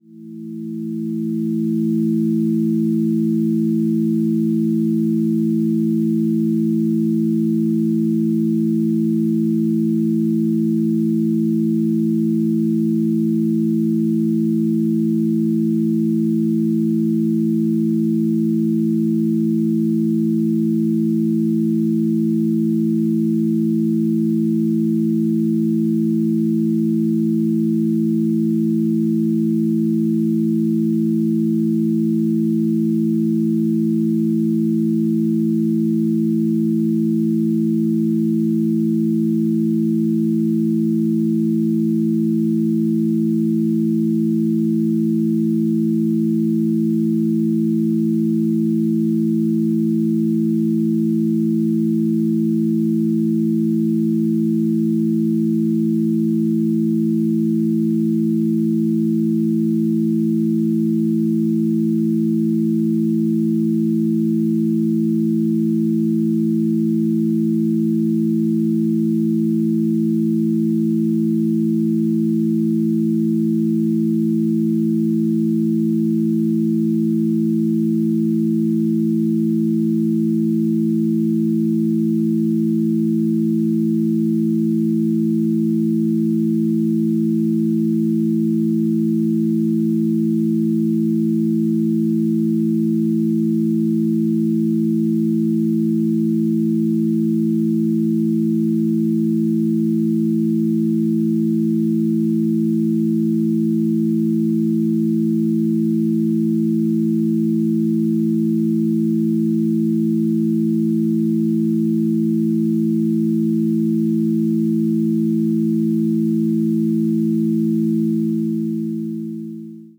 farm_ambient.wav